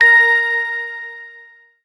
correct tone